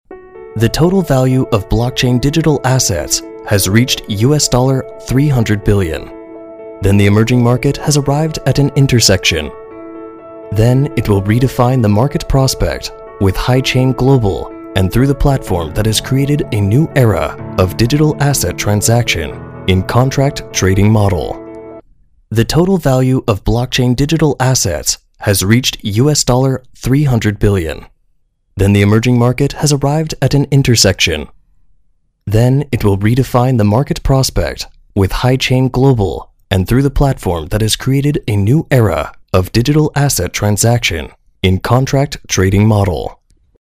• 美式英语配音